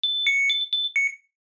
Download Free Household Appliances Sound Effects | Gfx Sounds
Appliance-power-on-jingle-startup-4.mp3